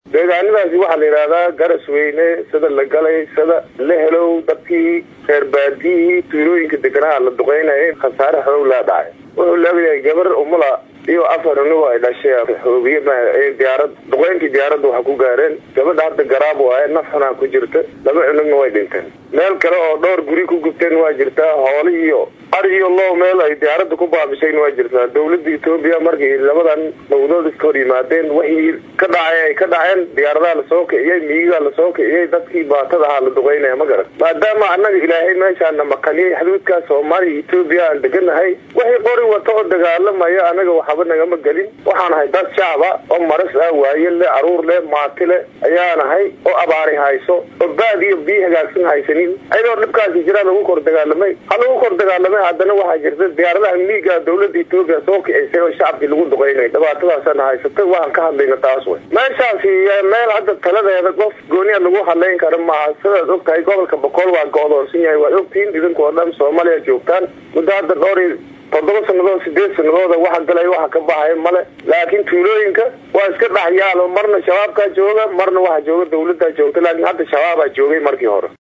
Goobjooge la hadlay warbaahinta Soomaaliya ayaa u sheegay in diyaaradaha duqeynta ay ka fuliyeen deegaannada uu ka dhashay khasaaro naf iyo maal ah.
Goobjooge-goobta-ay-Itoobiya-duqeysay.mp3